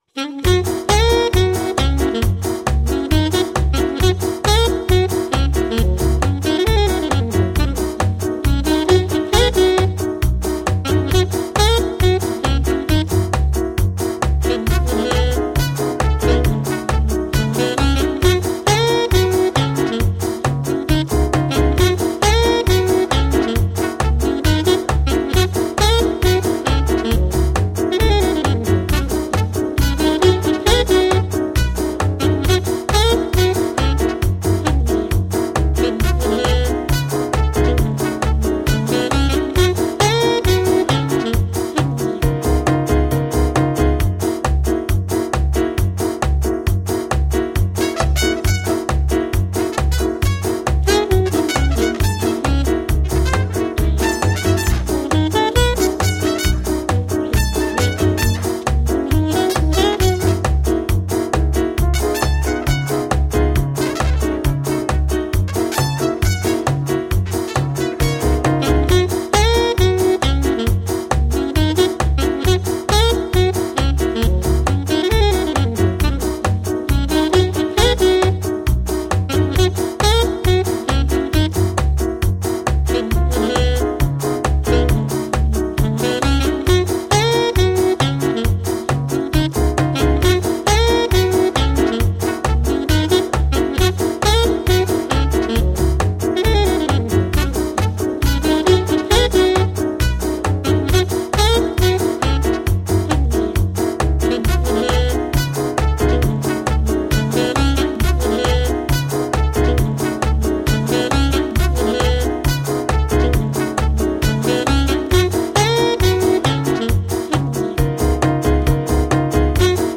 Смешная фоновая музыка без слов - отличный вариант